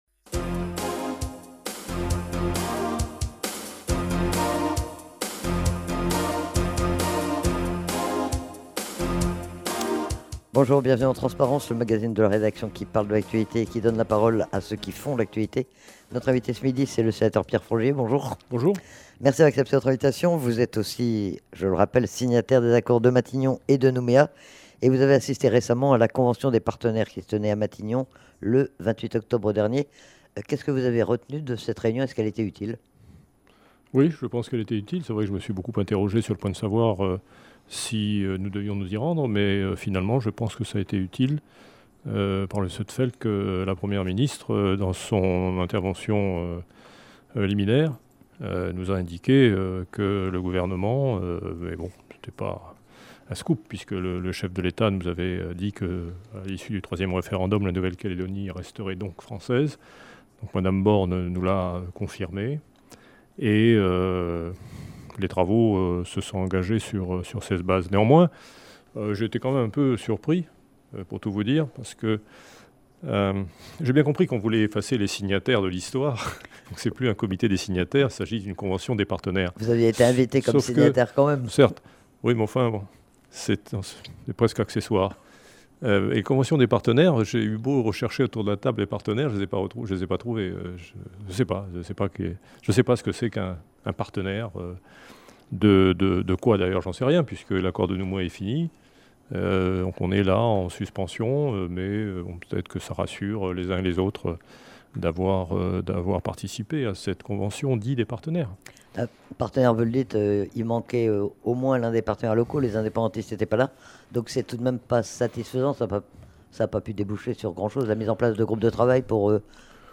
Le sénateur Pierre Frogier, signataire des accords de Matignon et de Nouméa, était interrogé sur l'actualité politique calédonienne : la convention des partenaires, la prochaine visite du ministre de l'intérieur et des outremers, les récents congrès indépendantistes… mais aussi sur sa vision de l'avenir et sur les propositions qu'il formule pour sortir la Nouvelle-Calédonie de l'impasse dans laquelle elle se trouve.